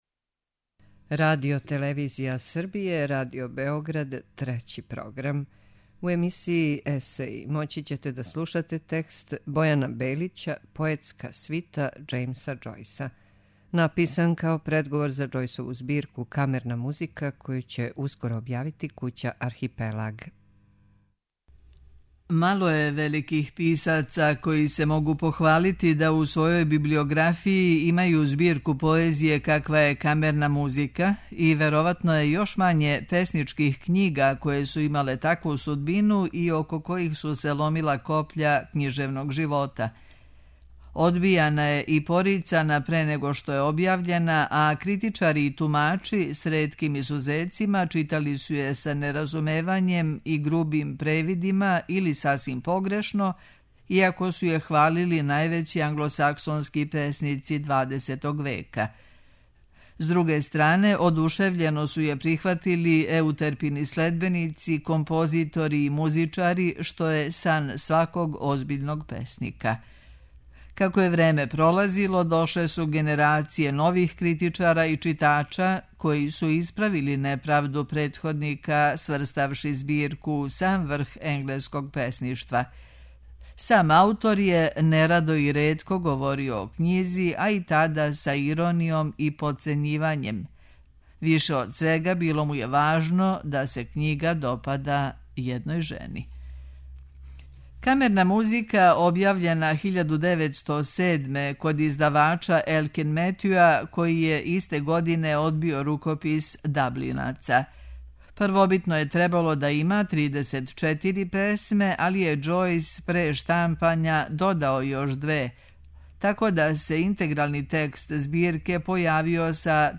Есеј